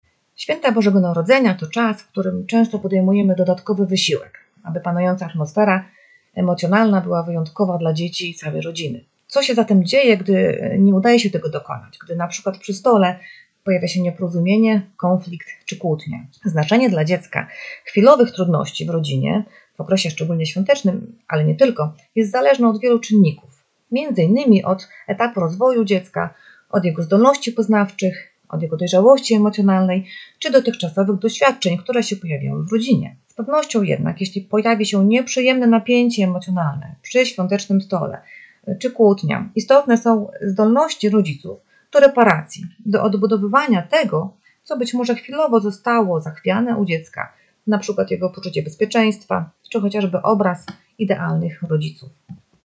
Rozmowa z psychologiem